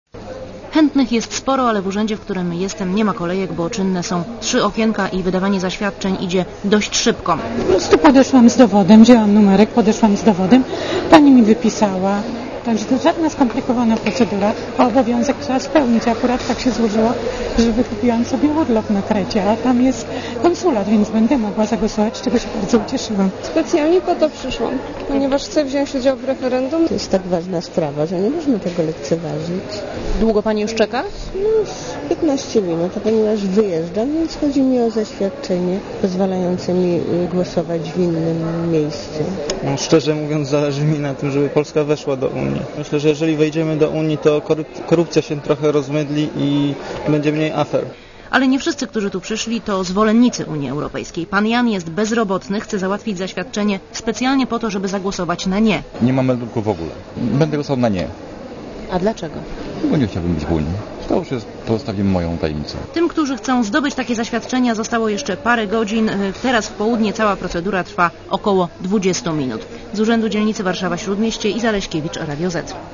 Zaświadczenia wydają urzędy gminy. W jednym z arszawskich urzędów był reporter Radia Zet.